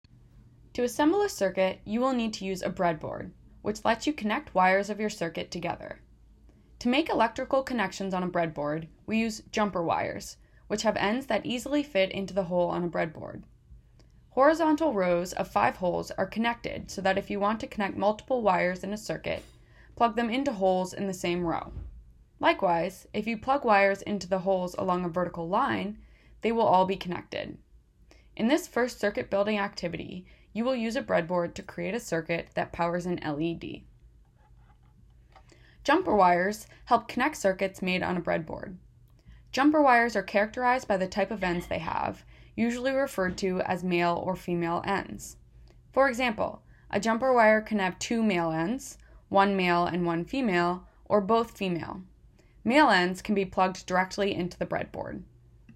Audio Read Along: Circuit Building Basics